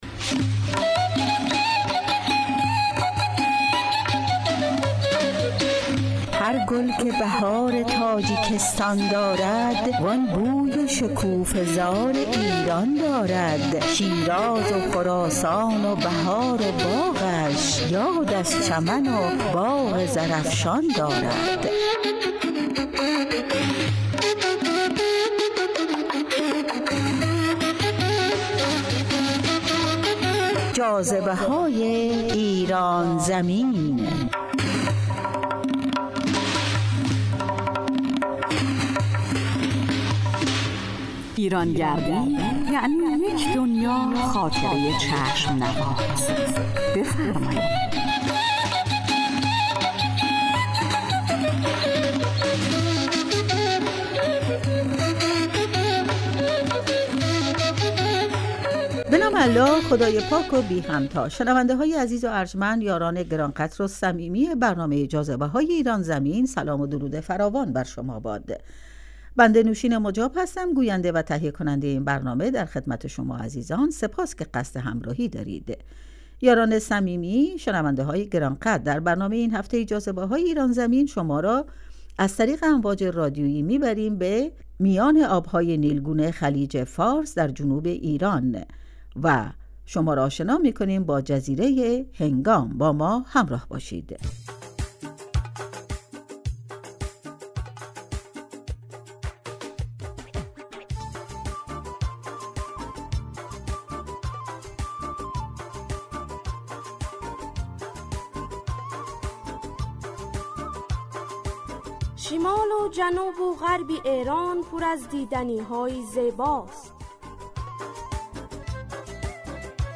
تهیه کننده و گوینده